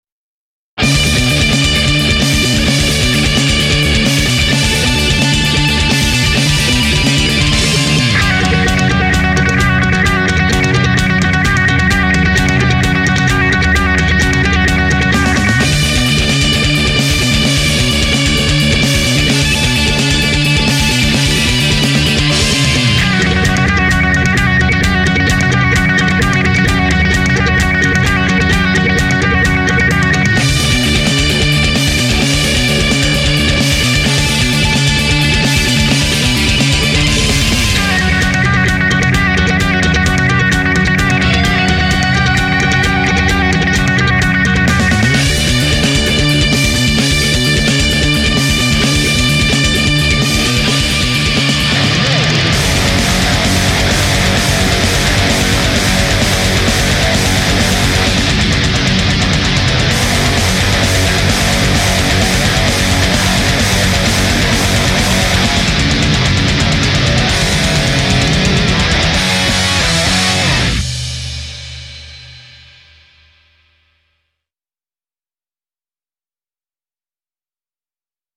Žánr: Rock